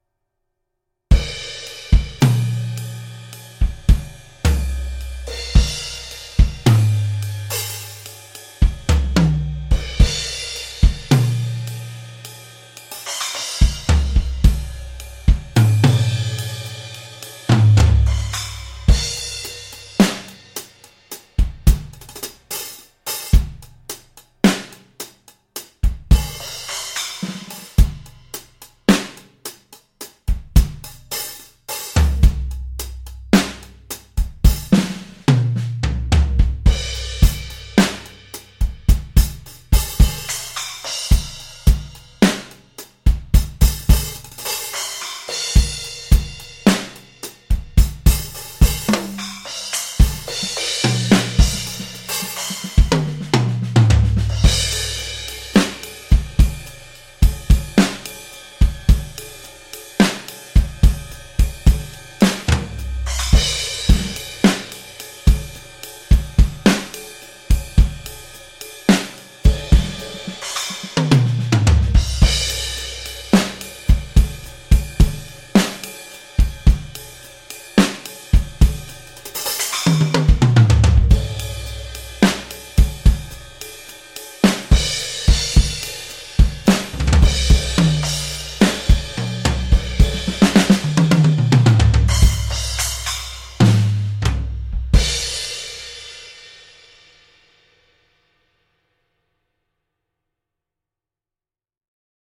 六 个原声鼓套件和超过 400 种经典鼓机声音
在英国伦敦的英国格罗夫工作室拍摄
为了增加更加有机和正确的时期风味，所有鼓和机器在数字转换之前都被记录到磁带上。